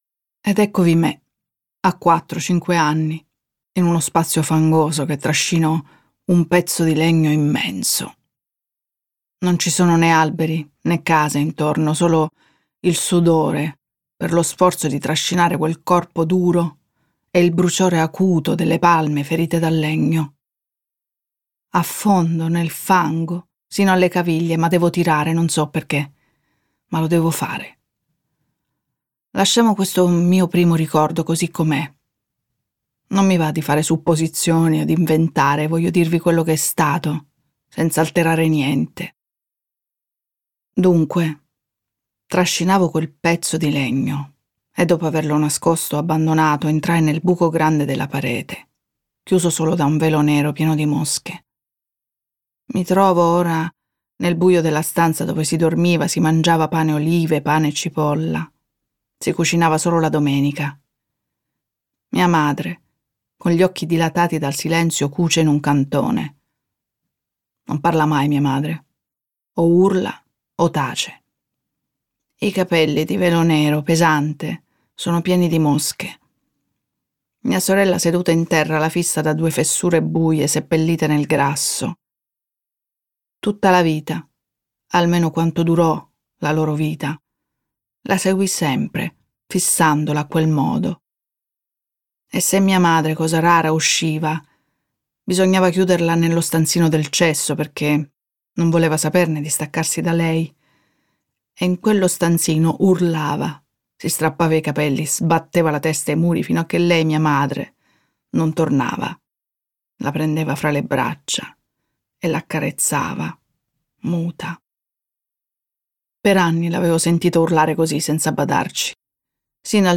letto da Donatella Finocchiaro
Versione audiolibro integrale